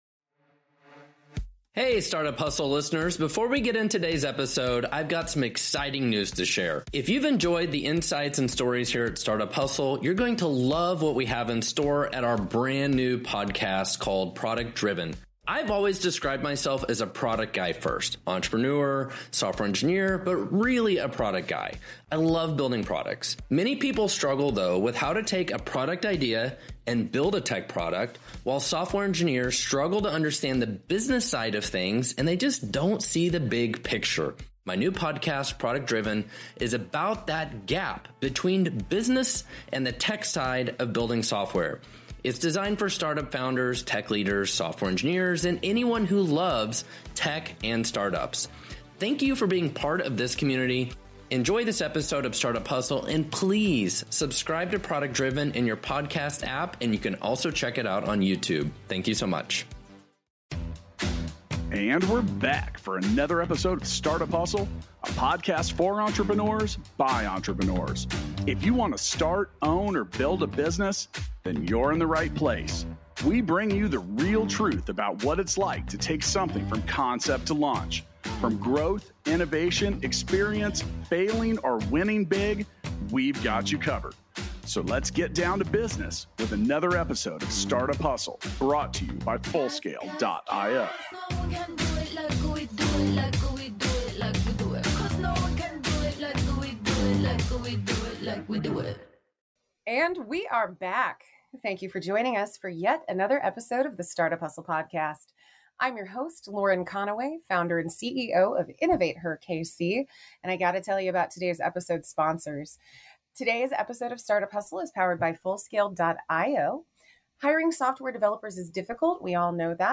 for a conversation surrounding Oklahoma's Venture Capital Scene. Hear them discuss how Oklahoma is shifting into an entrepreneurial economy and how great entrepreneurial ecosystems are built through supportive business environments.